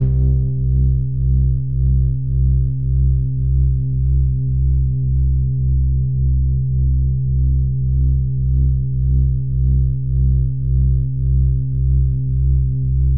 Pop Style Bass.wav